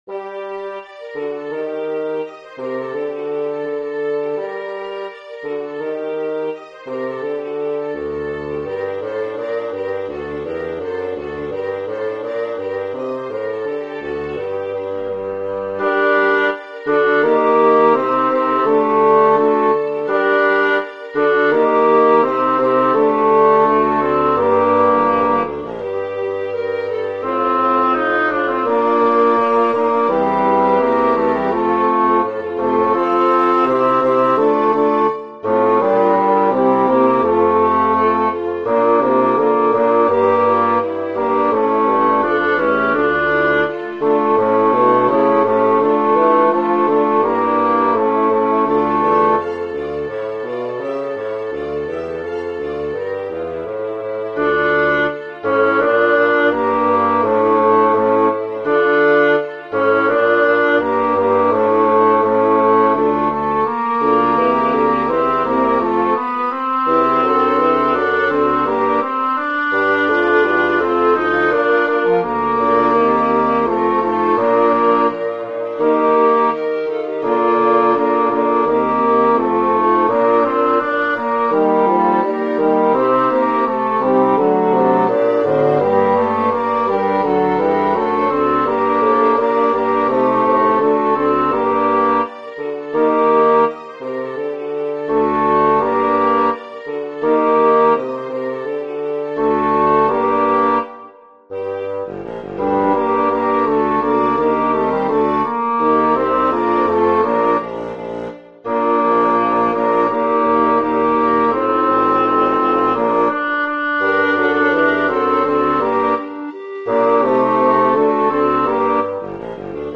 Number of voices: 4vv Voicing: SATB Genre: Sacred, Chorale
Language: Spanish Instruments: Basso continuo